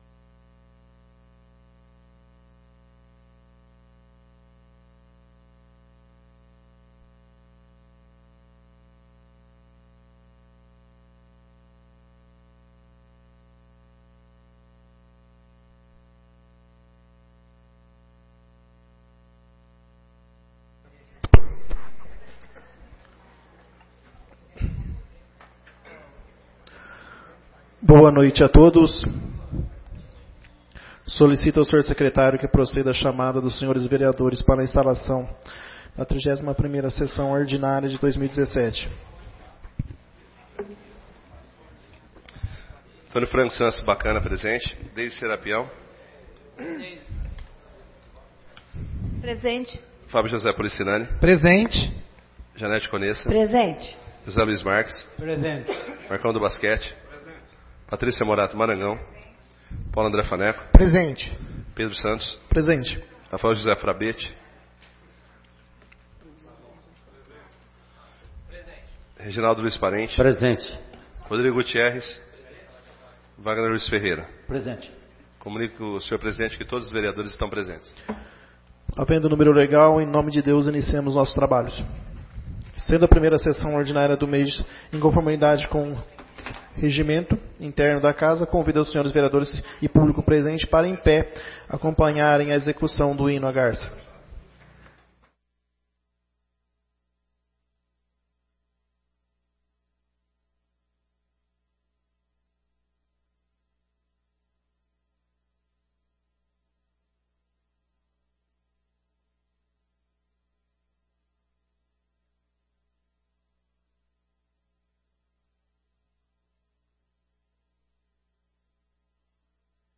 31ª Sessão Ordinária de 2017 — Câmara Municipal de Garça